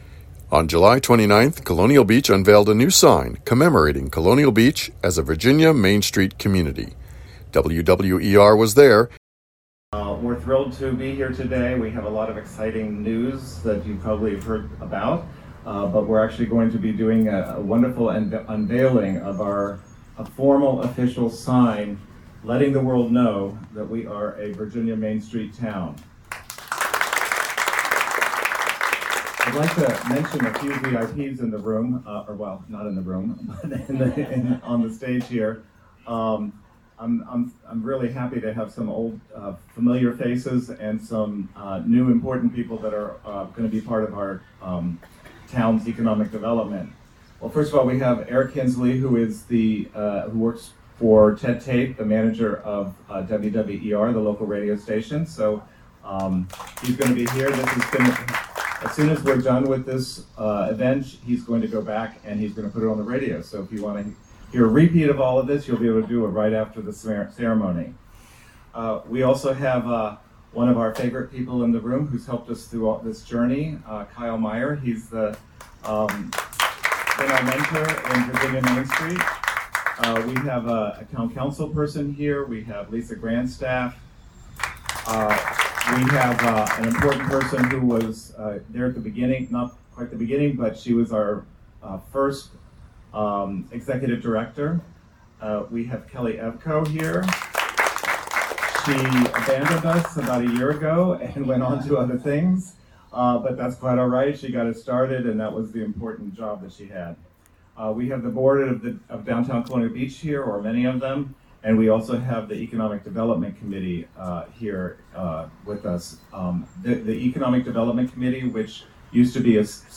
Main Street sign unveiling - WWER 88.1 FM
MainStreetSpeakers LONG.mp3